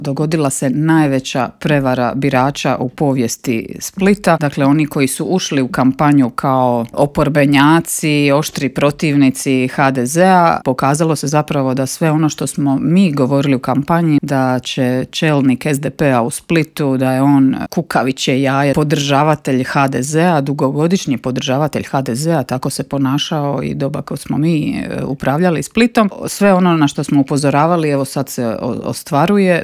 ZAGREB - U Intervjuu Media servisa ugostili smo saborsku zastupnicu i splitsku gradsku vijećnicu Centra Marijanu Puljak.